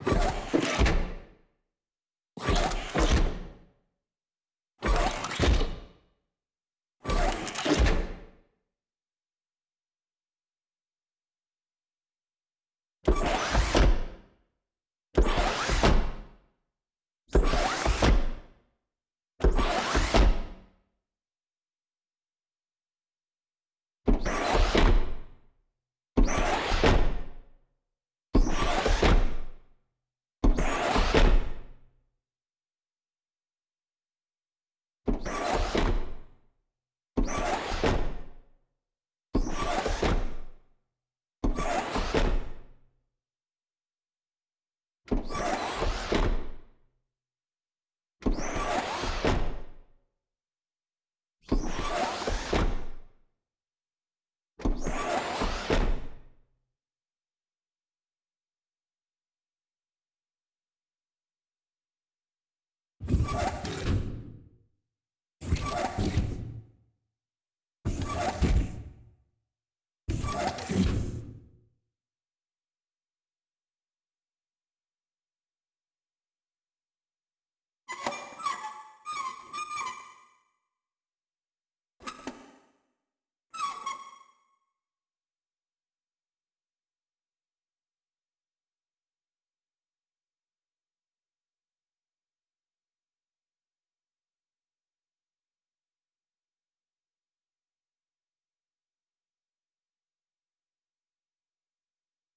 SFX_RoboSteps_Squeaky_05.wav